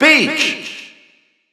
Spanish Announcer announcing Peach.
Peach_Spanish_Announcer_SSBU.wav